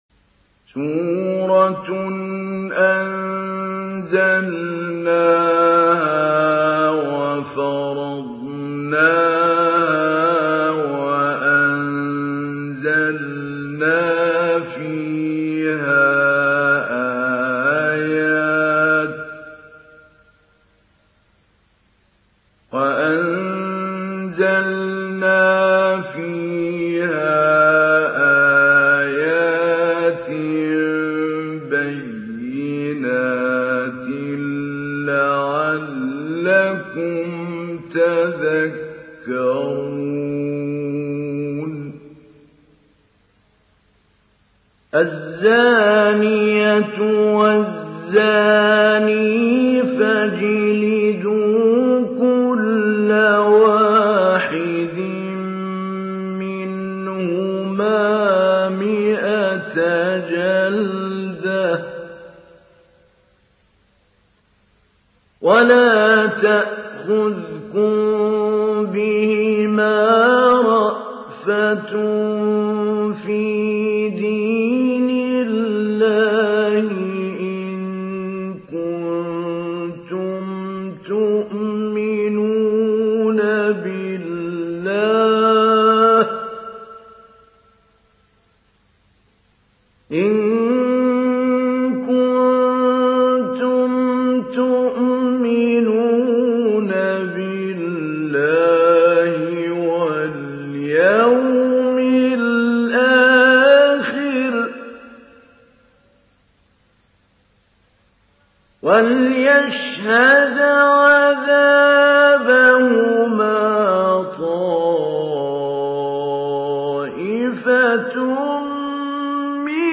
সূরা আন-নূর ডাউনলোড mp3 Mahmoud Ali Albanna Mujawwad উপন্যাস Hafs থেকে Asim, ডাউনলোড করুন এবং কুরআন শুনুন mp3 সম্পূর্ণ সরাসরি লিঙ্ক
ডাউনলোড সূরা আন-নূর Mahmoud Ali Albanna Mujawwad